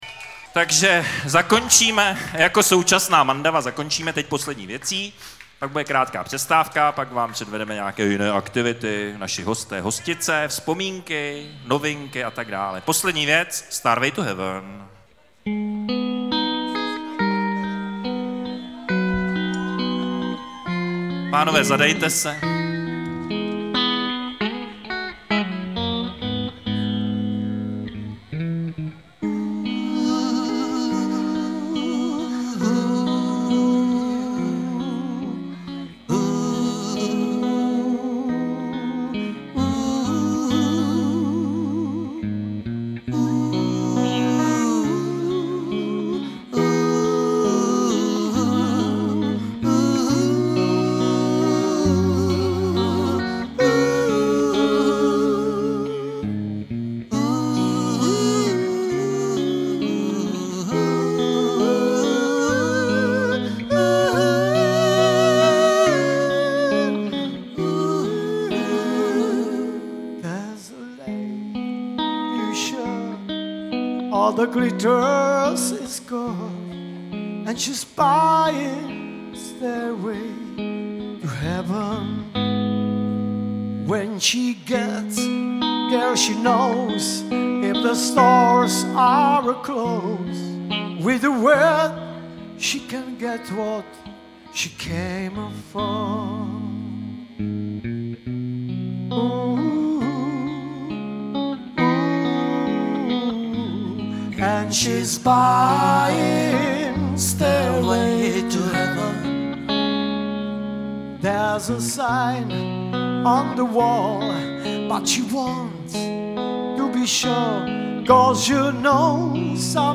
MP3 - Klub Kino Černošice (záznam koncertu)
zpěv, kytary
baskytara, zpěv
bicí, zpěv